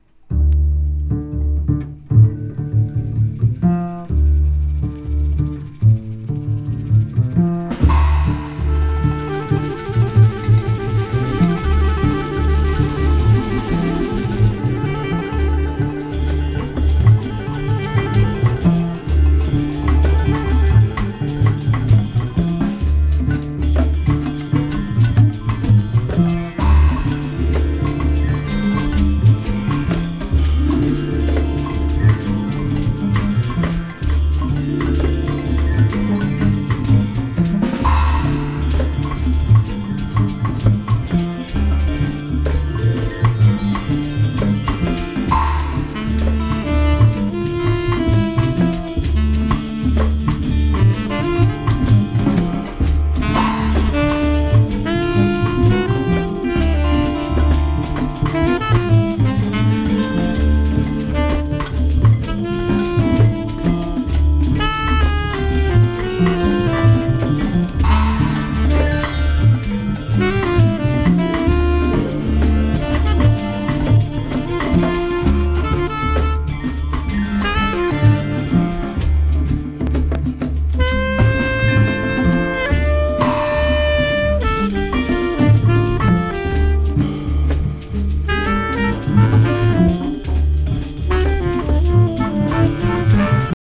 ジャケットからも漂う怪しさはスピリチュアルジャズと言われるジャンルに分類される内容です。